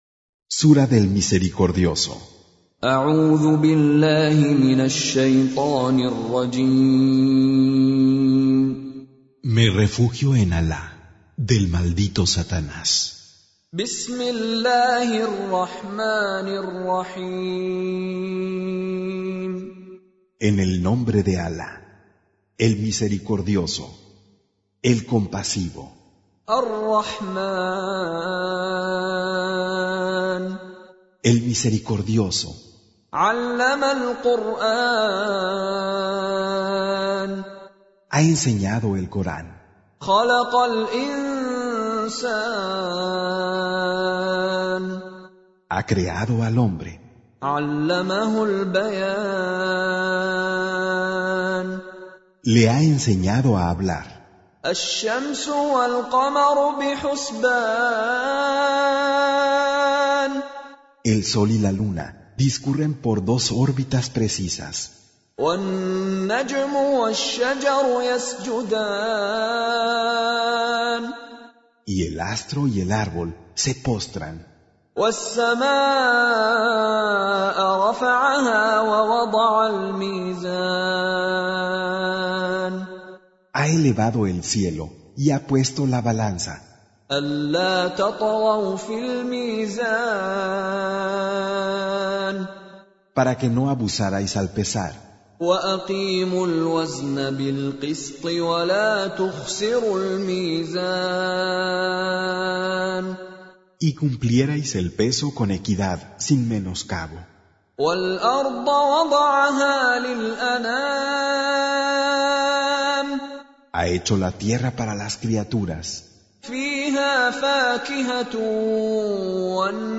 Recitation
Con Reciter Mishary Alafasi